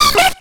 Cri de Coxy dans Pokémon X et Y.